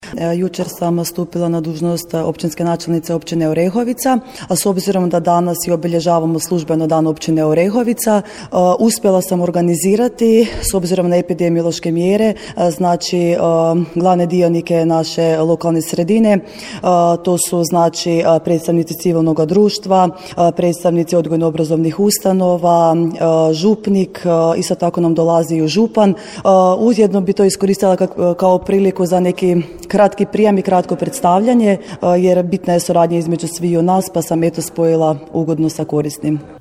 Dan Općine Orehovica 21. svibnja novoizabrana načelnica Dijana Novak, koja je samo dan ranije stupila na dužnost, iskoristila je za kratko predstavljanje i razgovor s predstavnicima civilnog društva i institucija s područja općine.